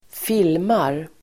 Uttal: [²f'il:mar]
filmar.mp3